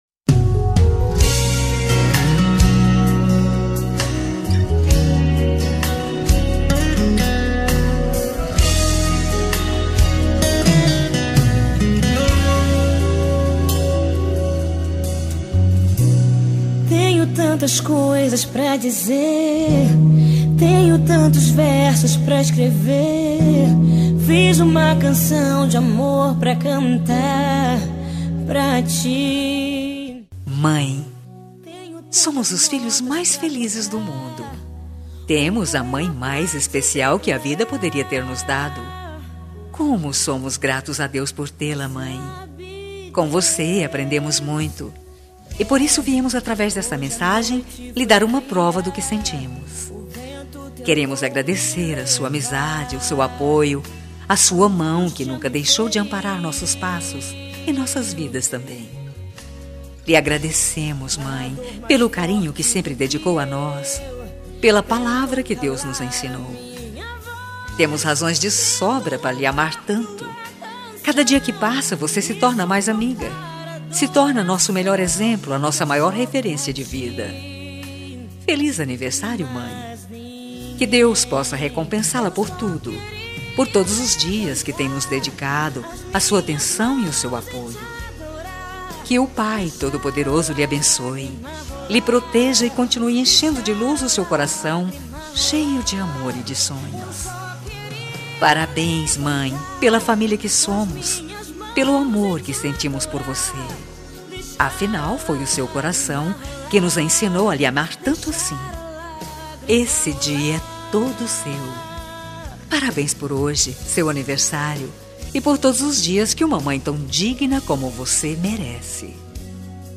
Telemensagem Evangélica Anversário Mãe | Com Reação e Recado Grátis
26-ANIV-EVANG-MAE-FEM-03.mp3